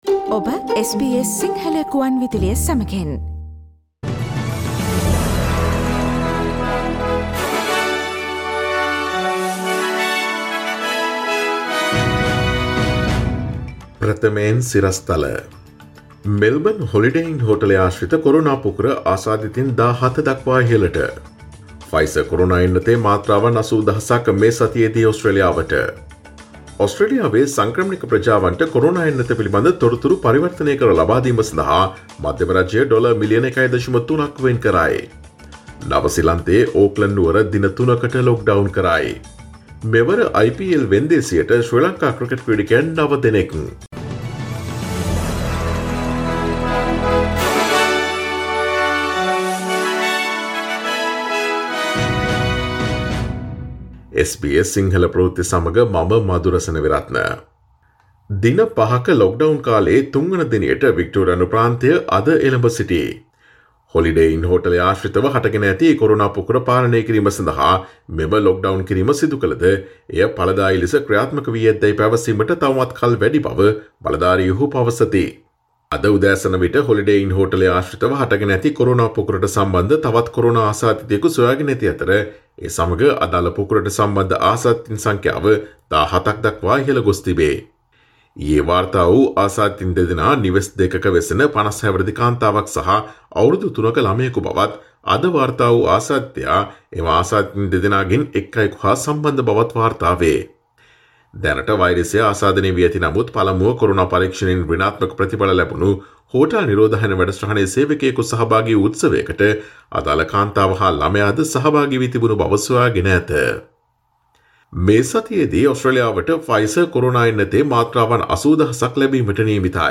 Today’s news bulletin of SBS Sinhala radio – Monday 15 February 2021.